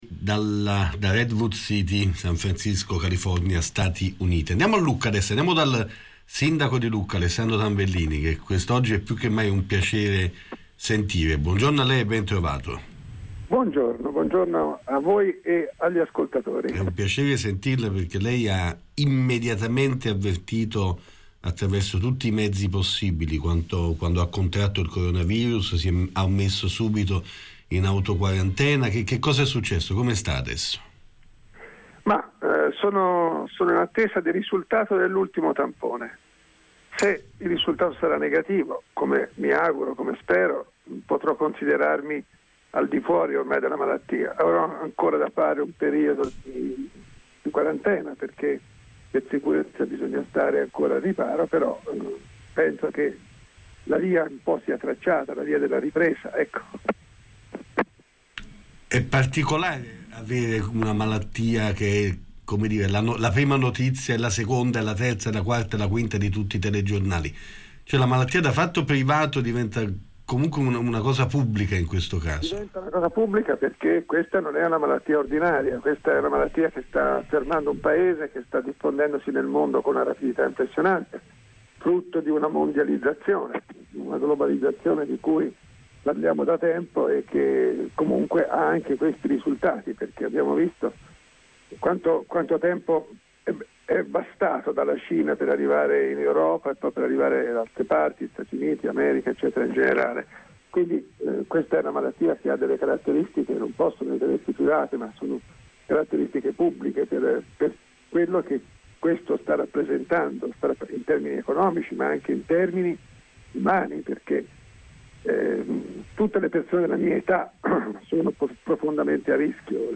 La testimonianza del Sindaco di Lucca in quarantena per Coronavirus.